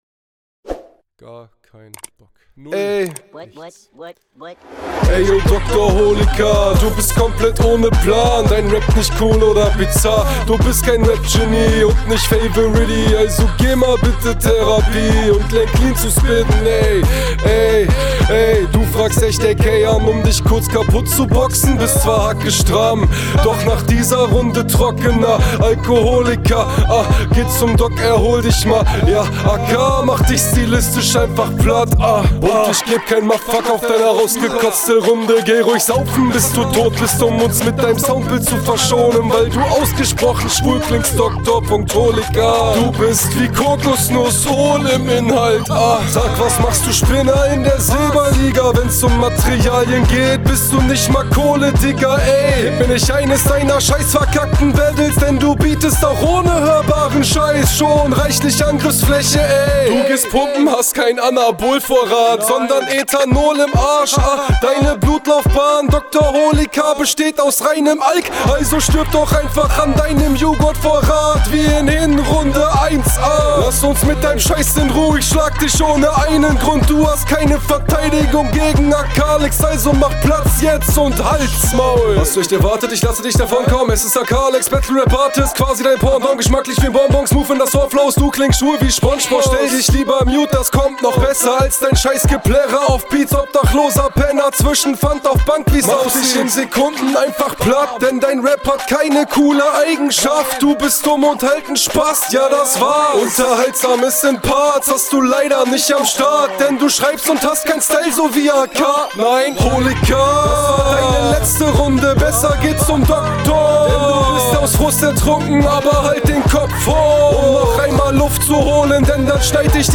Auf dem Beat kommst schonmal besser.
Am besten gefällt mir bei dir der Stimmeinsatz und deine Reime.